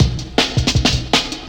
RINGO 160BPM.wav